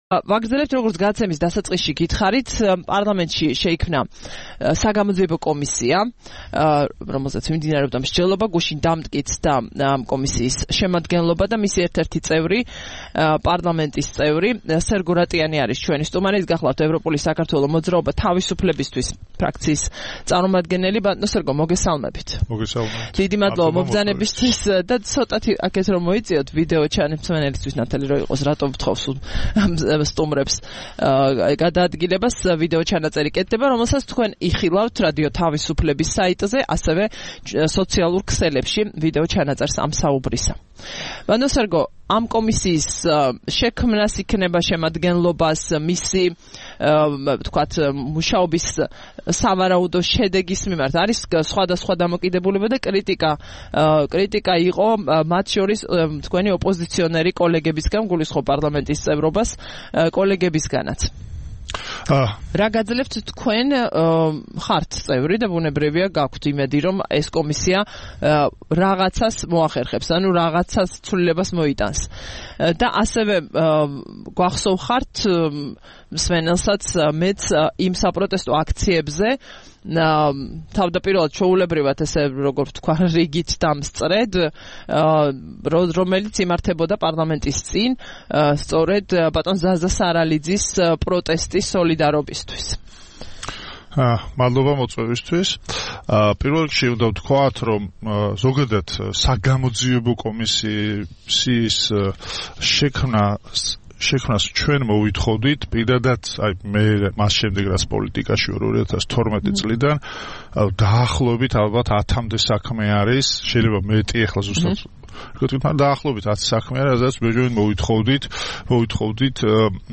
7 ივნისს რადიო თავისუფლების "დილის საუბრების" სტუმარი იყო სერგო რატიანი, პარლამენტის წევრი.